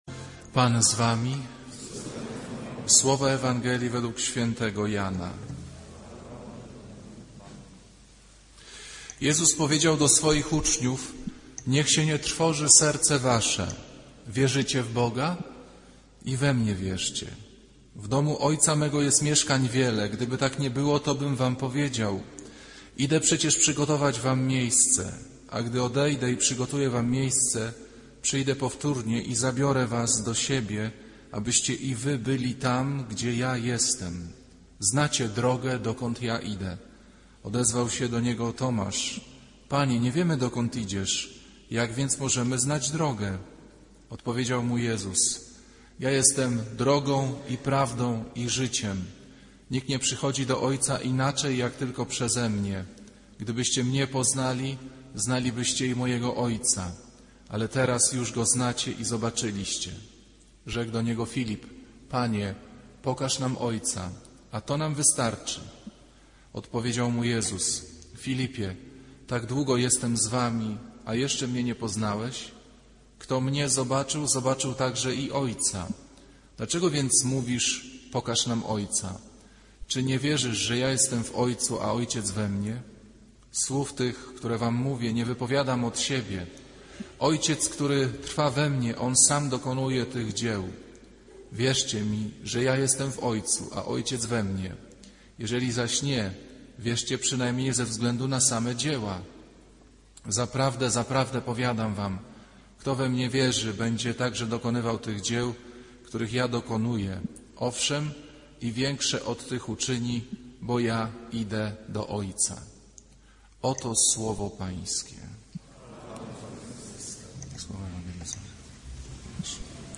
Kazanie z 20 kwietnia 2008r.